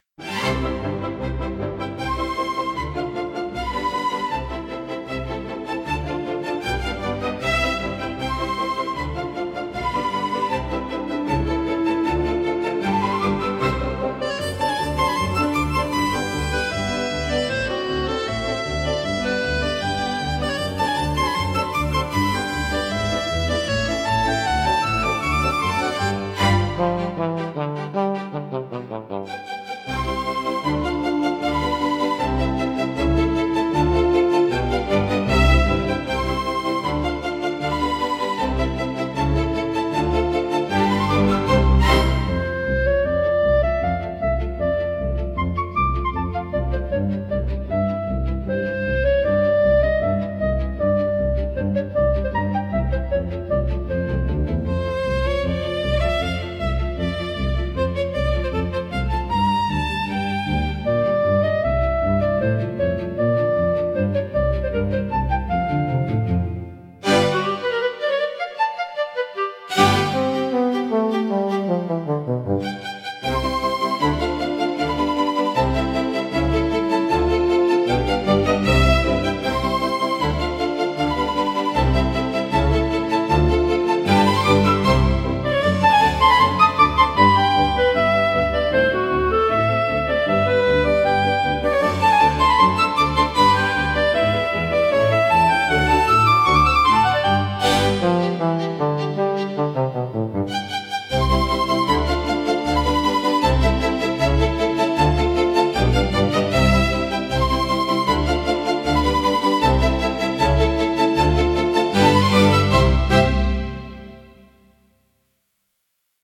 聴く人に高雅さや荘厳さ、心の深みを感じさせる普遍的なジャンルです。